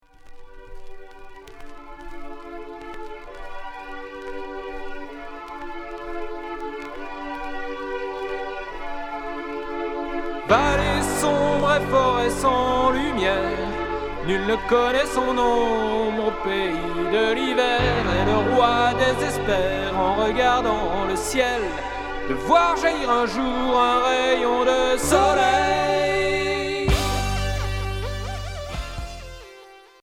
Pop progressif Unique 45t retour à l'accueil